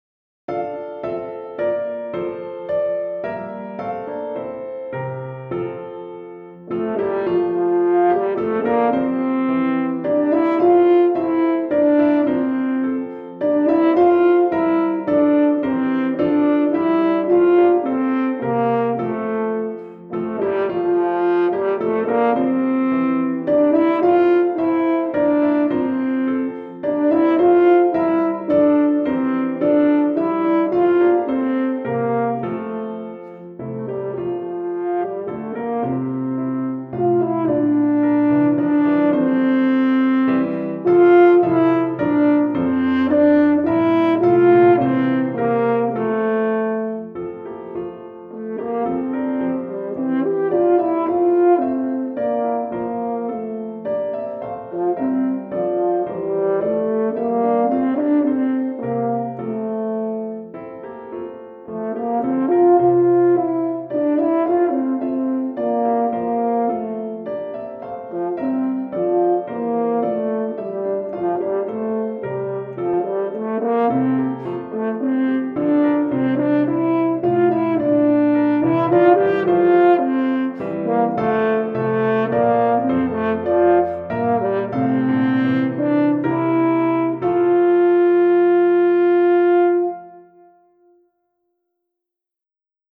Cor et Piano